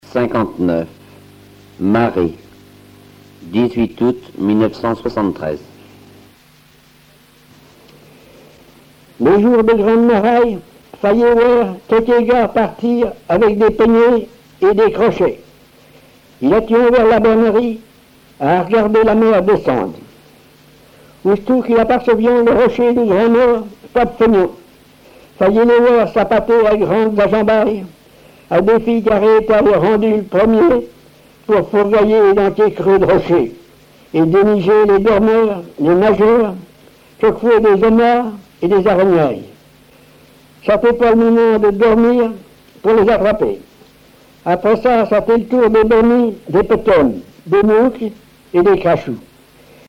Patois local
Genre récit
Récits en patois